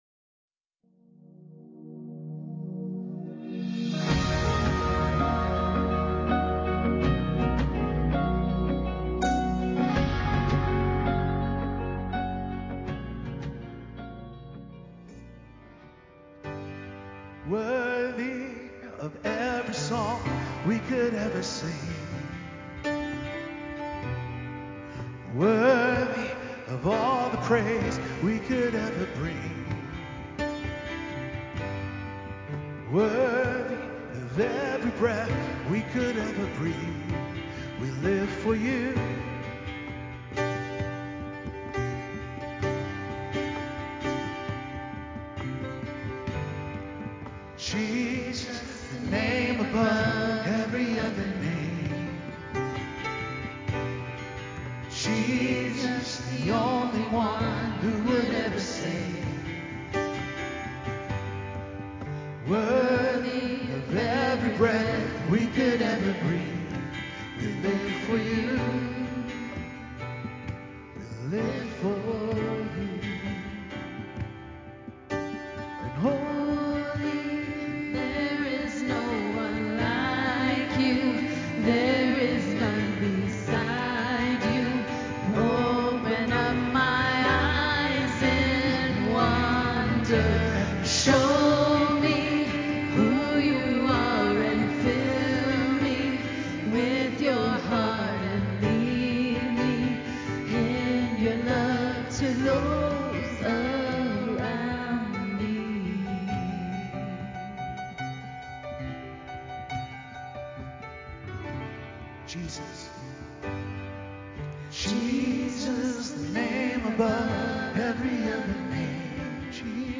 Discovering Greater Joy Through Foundational Truths: A Post-Christmas Sermon – Grace Fellowship
Building-A-Rock-Solid-Faith-Full-Service-CD.mp3